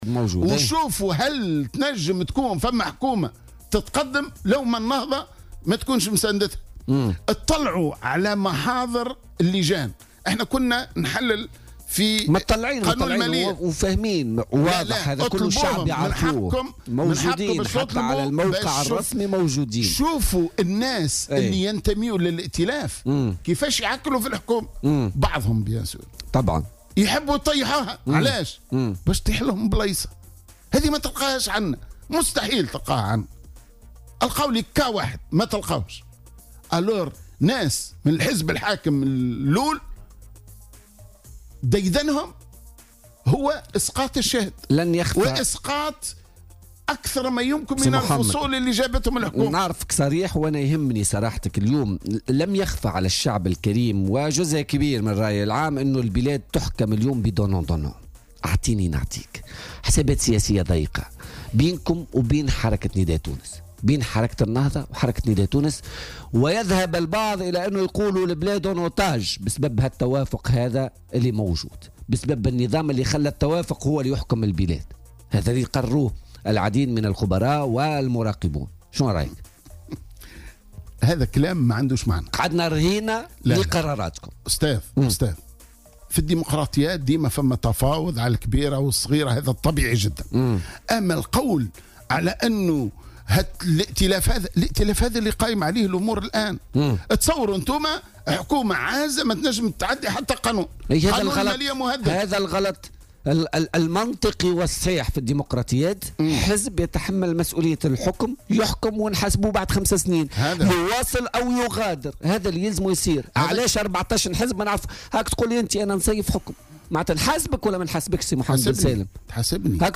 وقال بن سالم خلال استضافته اليوم في برنامج "بوليتيكا"، إن نواب نداء تونس من جهة يعلنون مساندتهم للحكومة ودعمهم لرئيسها 'ابن النداء"، ومن جهة أخرى يتموقعون في صفوف المعارضة ضده، ومداخلات بعضهم خلال الجلسة العامة المخصصة لمناقشة مشروع قانون المالية لسنة 2018 تؤكّد ذلك، حسب تعبيره.